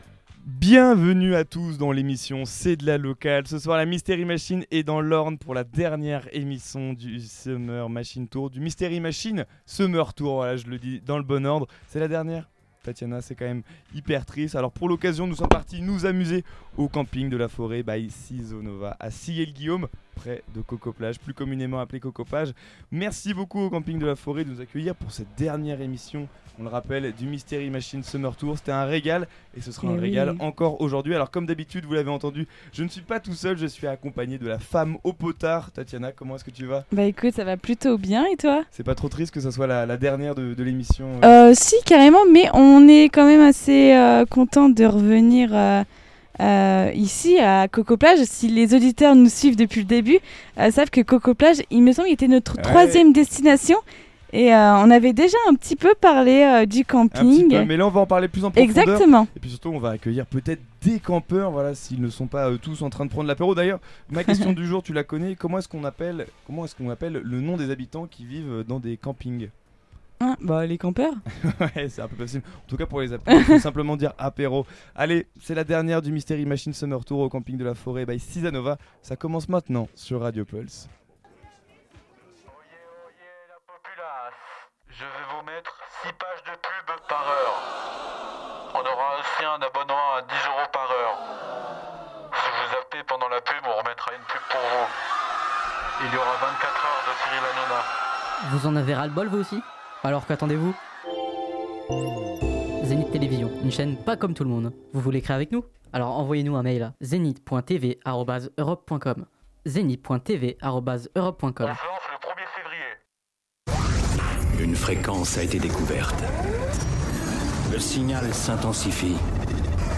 Cet été, nous organisons la Mystery Machine Summer Tour, c’est-à-dire une émission en direct de 18h à 19h, diffusée depuis différentes villes et villages de l’Orne et de la Normandie. Dans cette émission, l’objectif est de présenter la ville, les activités à y faire, son histoire, les acteurs de la vie culturelle et associative, ainsi que les différents événements prévus cet été.
Mais cette fois-ci, c'est une édition spéciale au Camping de la Forêt Seasonova.
Une émission où les invités étaient au rendez-vous, ainsi que les rires et la convivialité.